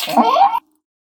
jump.ogg